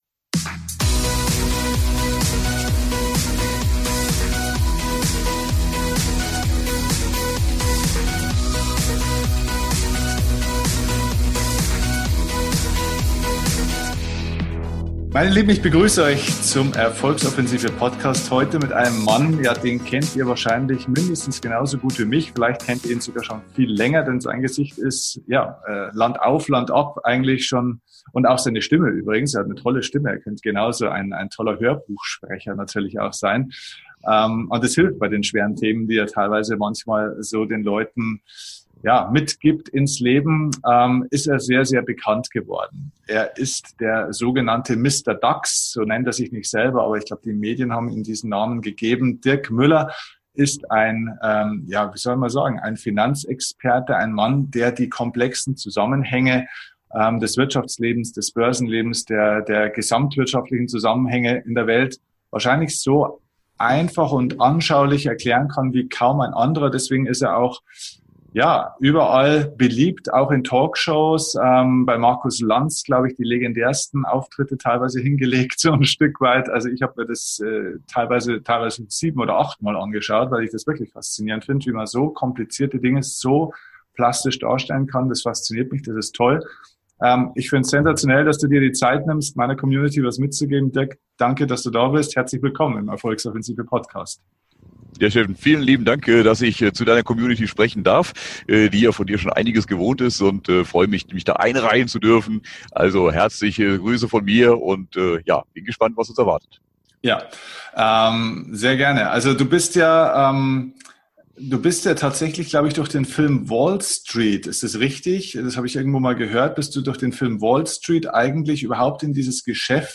#216 Mr. DAX packt aus! - Finanzexperte und Bestsellerautor Dirk Müller im Gespräch | Aktien | Börsenhandel | Geldanlage | ~ DIE KUNST ZU LEBEN - Dein Podcast für Lebensglück, moderne Spiritualität, emotionale Freiheit und berufliche Erfüllung Podcast